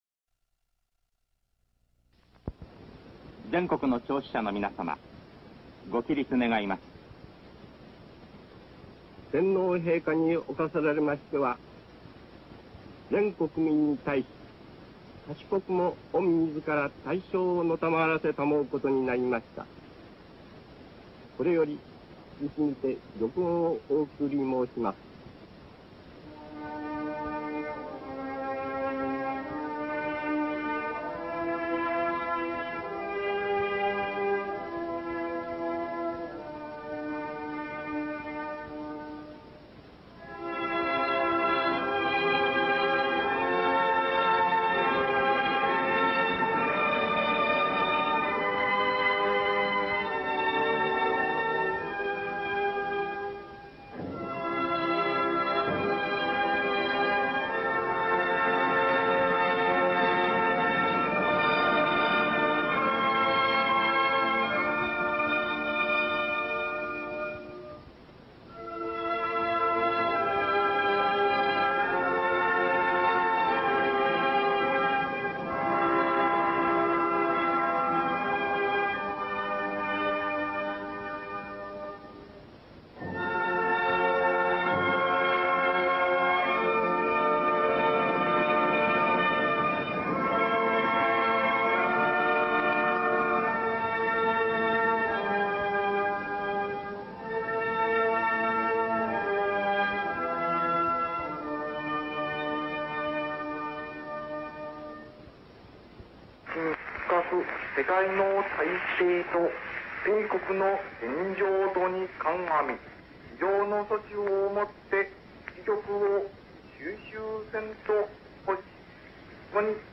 玉音放送-ポツダム宣言.mp3